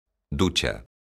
ducha_son.mp3